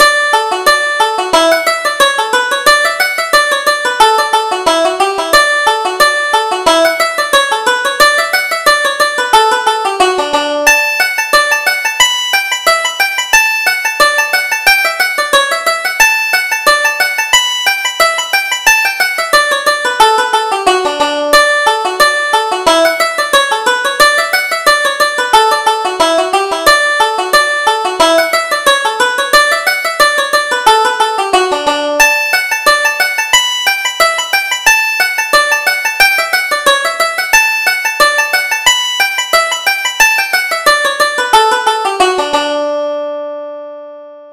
Reel: The Butcher's Apron